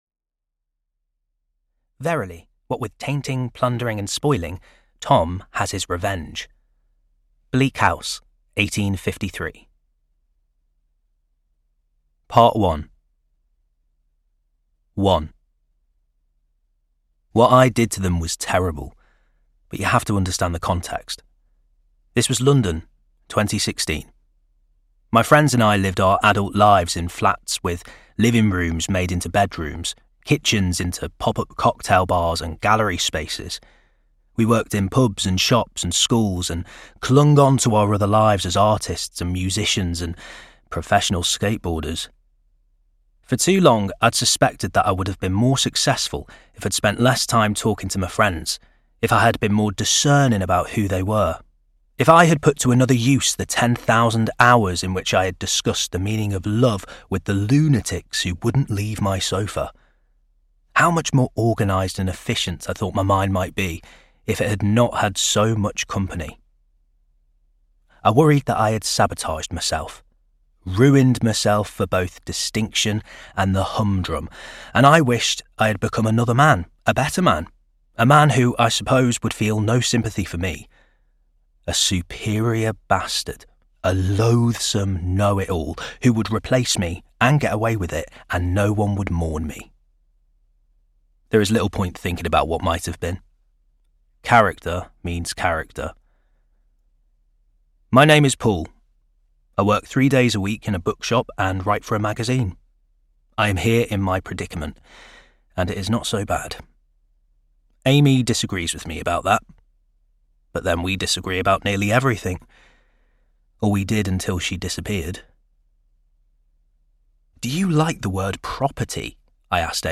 Theft (EN) audiokniha
Ukázka z knihy